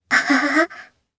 Kara-Vox_Happy3_jp.wav